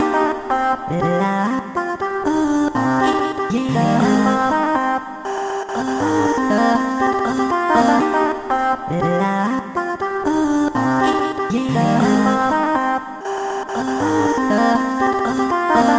WOAH声乐呼喊
标签： 200 bpm Trap Loops Vocal Loops 69.80 KB wav Key : Unknown
声道立体声